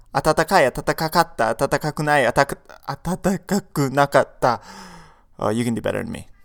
Fail!
fail-atatakai.mp3